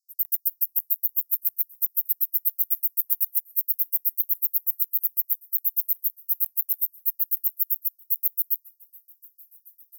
constricted shieldback
10 s of calling song and waveform. Santa Barbara County, California; 25.8°C. JCR130827_03.